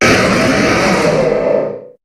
Cri de Méga-Galeking dans Pokémon HOME.
Cri_0306_Méga_HOME.ogg